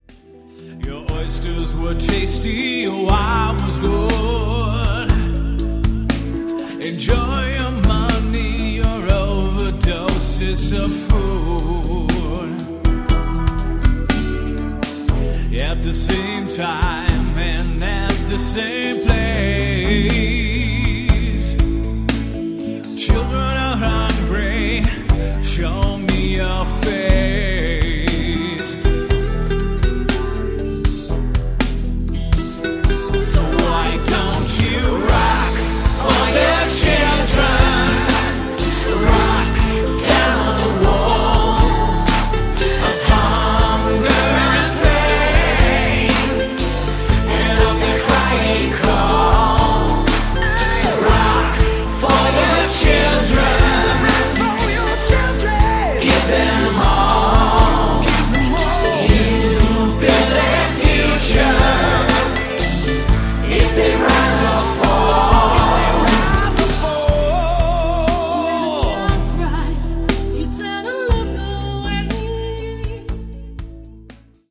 Ballade